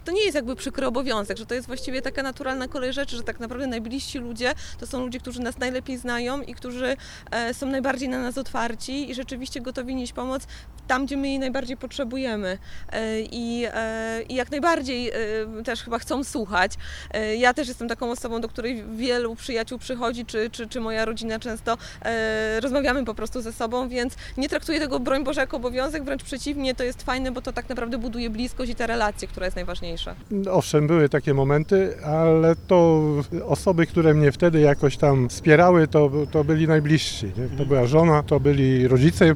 Przy okazji Światowego Dnia Słuchania zapytaliśmy mieszkańców Wrocławia i osoby odwiedzające nasze miasto o opinię nt. tego, co może wpływać na zamykanie się na rozmowę z innym człowiekiem: